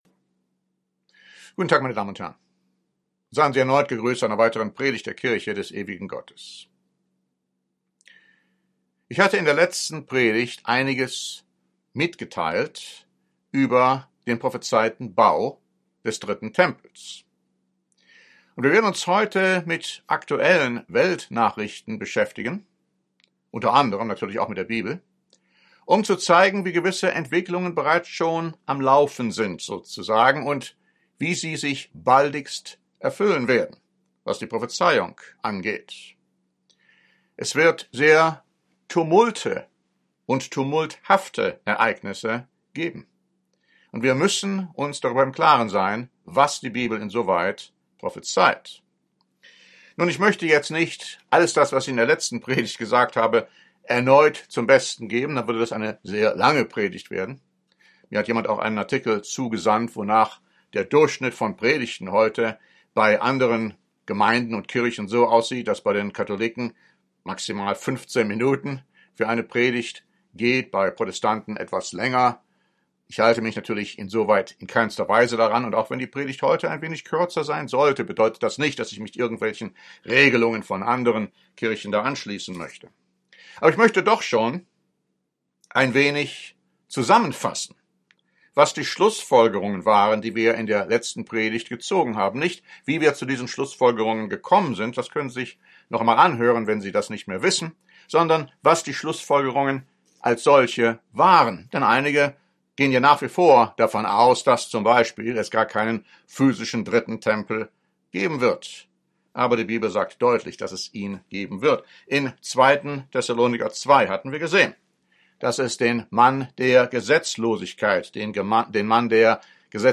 Die Fortsetzung der Predigt von letzter Woche über dieses Thema bringt zusätzliche Klarheit.